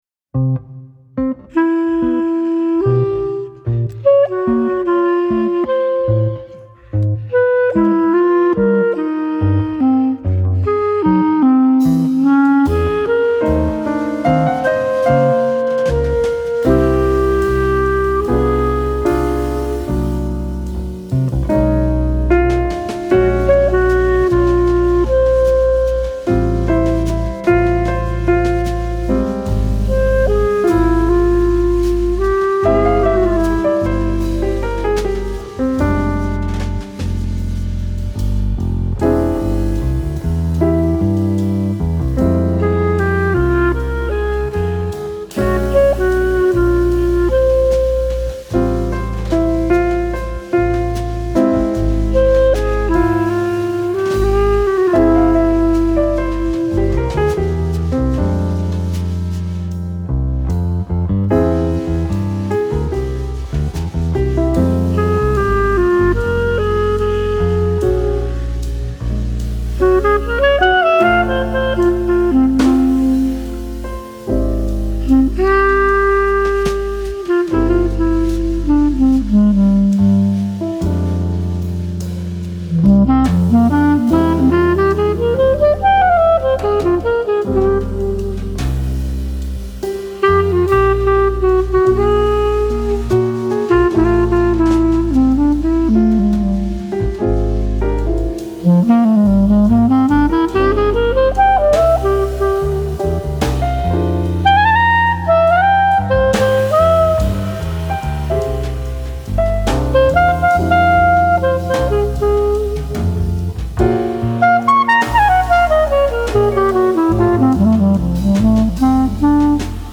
saksofon, klarnet
fortepian
perkusja.
gitara basowa.